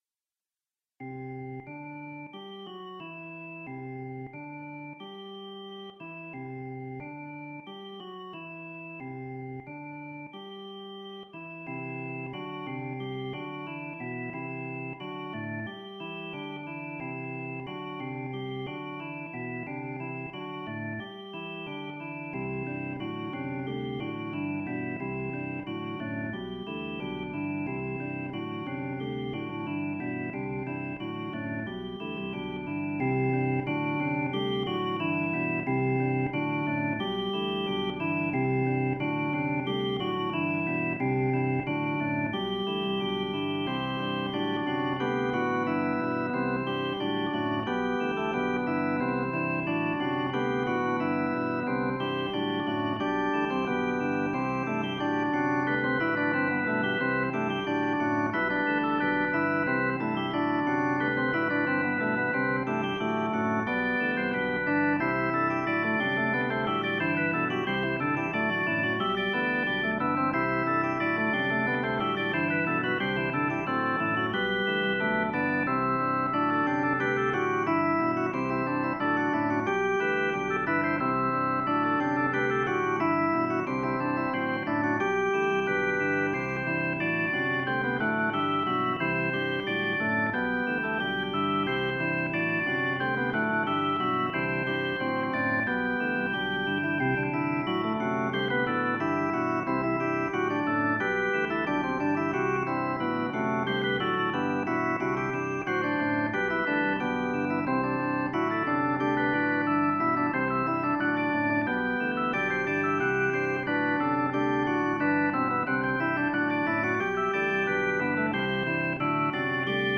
I also like chaconnes (way easier than fugues):
And another (on the chords from the theme tune of The Big Bang Theory of all things) with a two-part canon on top, for organ.